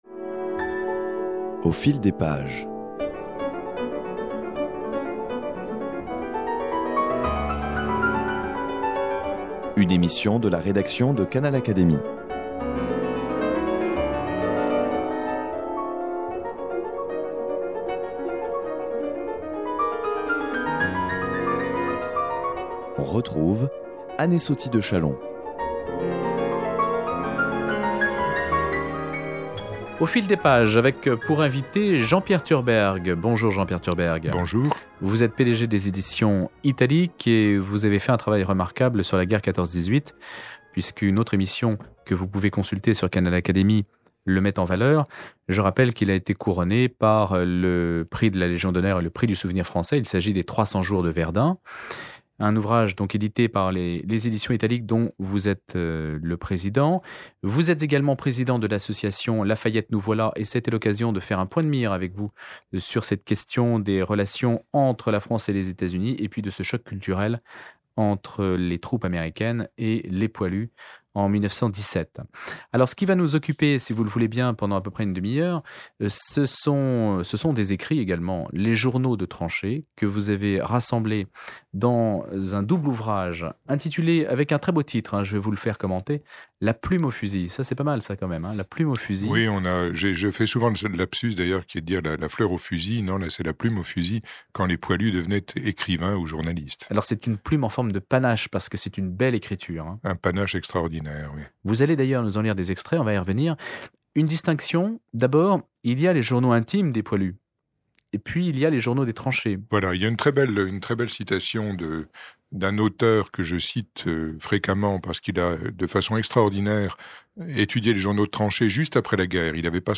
Dans cet entretien